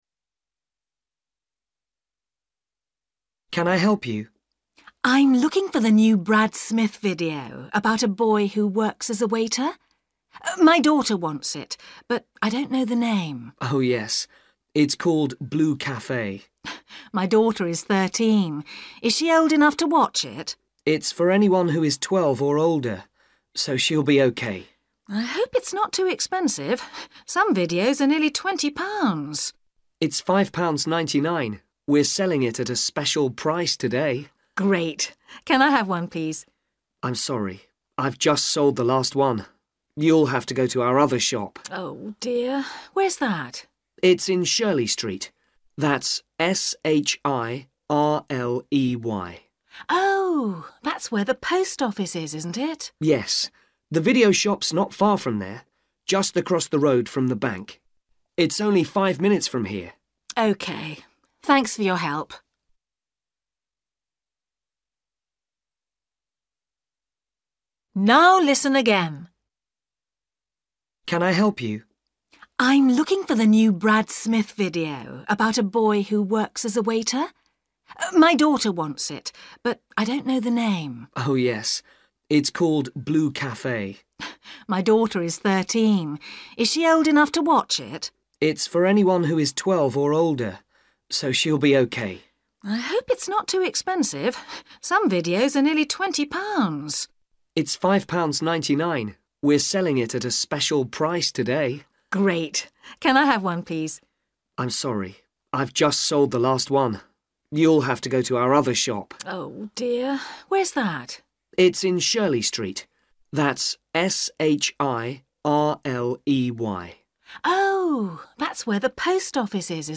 You will hear a woman talking to a shop assistant about buying a video film for her daughter.
You will hear the conversation twice.